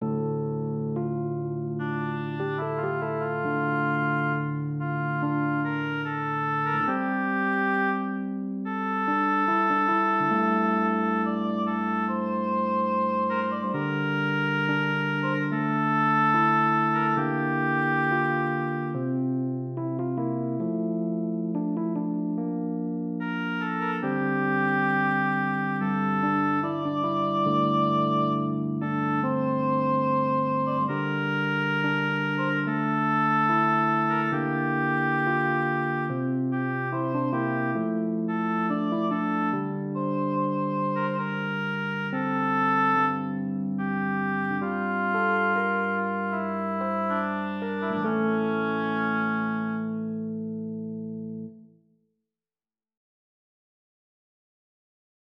Organ/Organ Accompaniment, Vocal Solo
Voicing/Instrumentation: Organ/Organ Accompaniment
Medium Voice/Low Voice